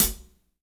-16  HAT 5-R.wav